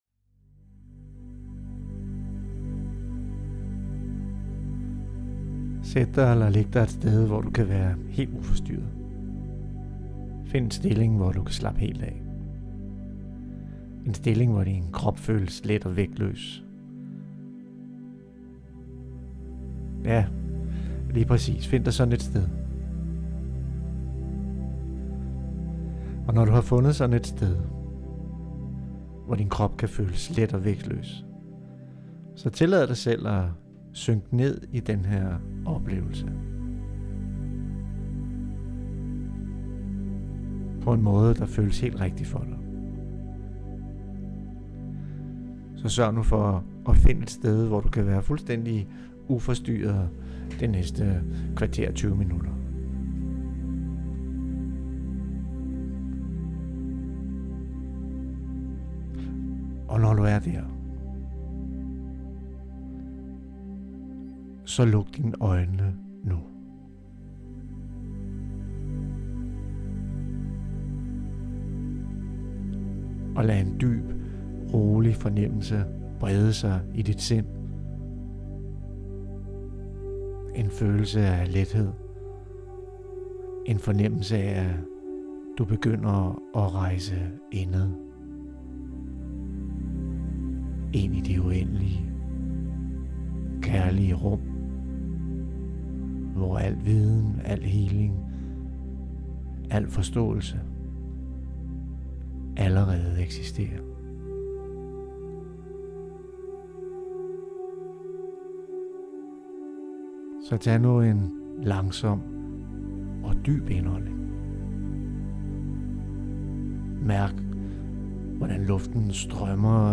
Her kan du lytte til min guidede meditation, med Healing & Svar